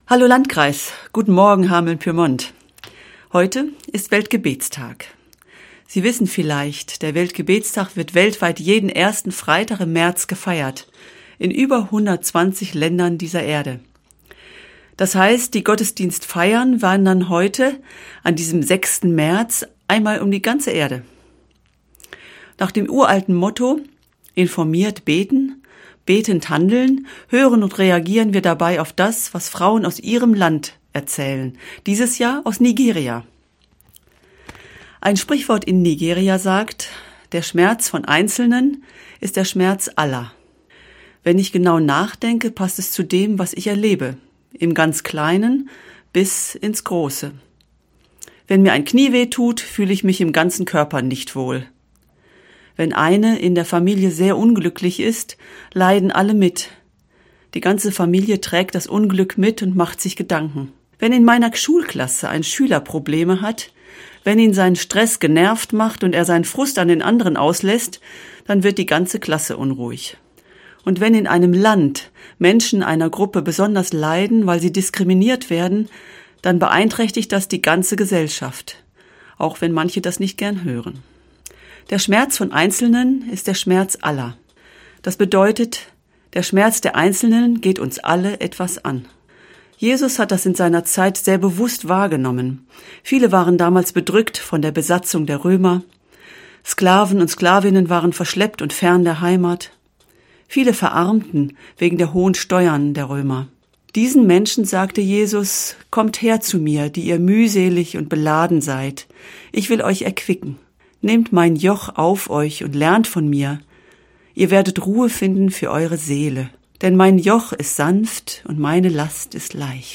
Radioandacht vom 6. März